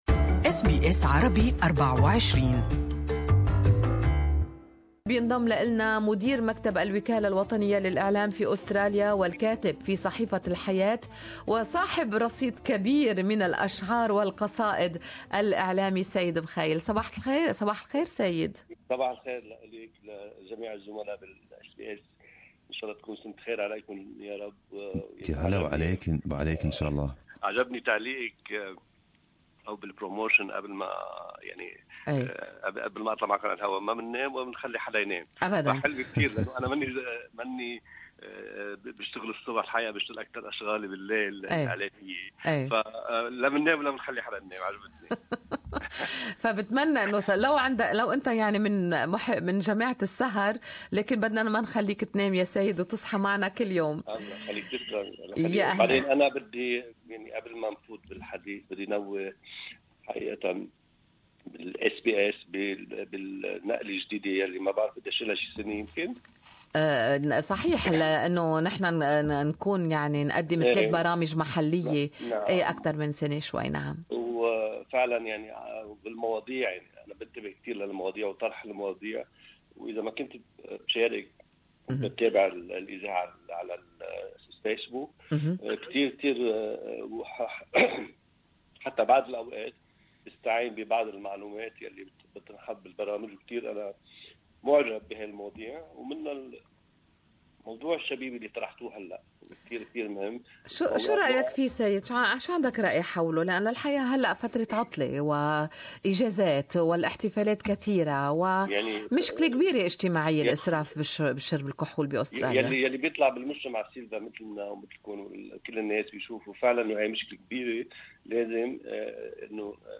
Good Morning Australia interviewed